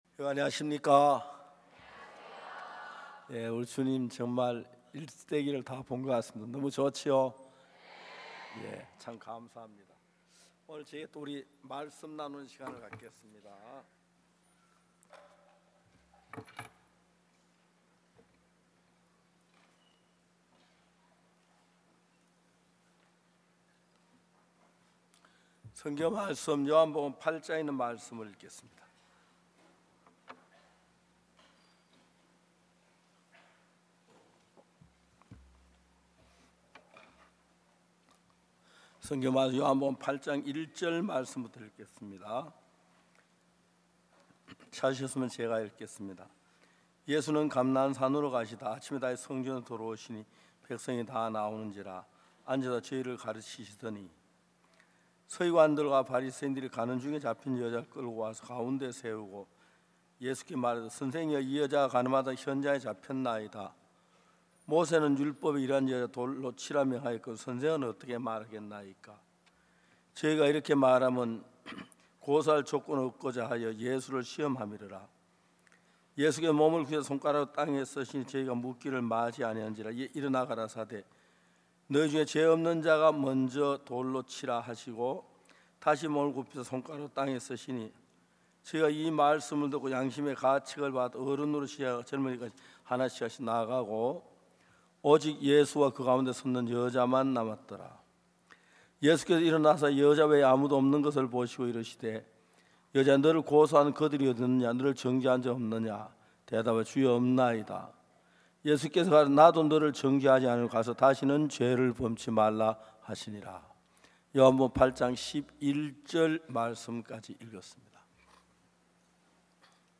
2025 전반기 대구성경세미나